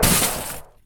frostimpact.wav